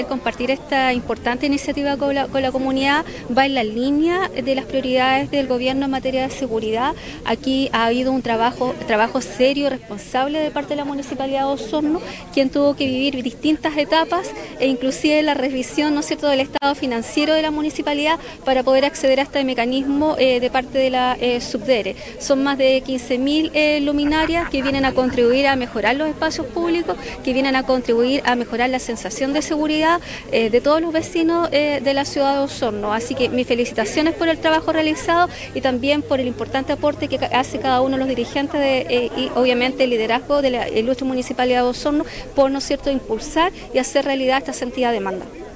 Este martes, se dio inicio al recambio de 15.872 luminarias en el radio urbano de Osorno, en un acto celebrado en la Plaza España de Rahue Alto.
Del mismo modo, la Delegada Presidencial Provincial, Claudia Pailalef, señaló que desde el Gobierno es una prioridad trabajar en la seguridad de la comunidad.